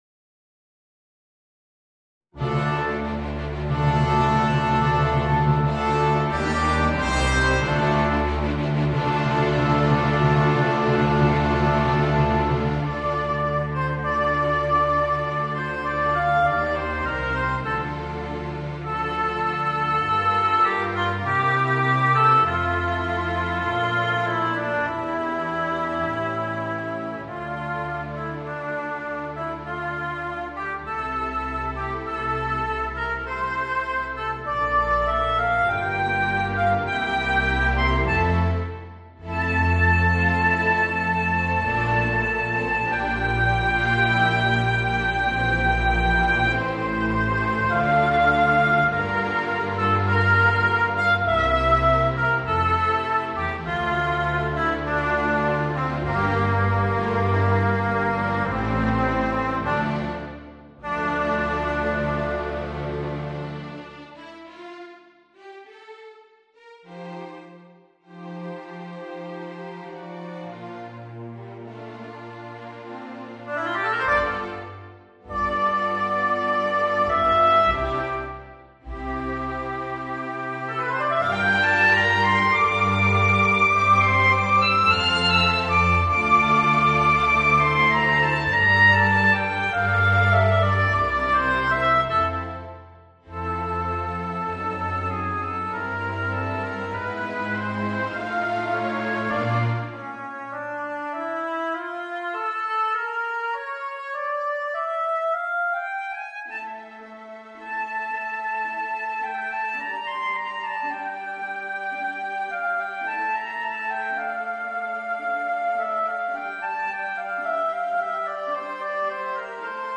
Voicing: Oboe and Orchestra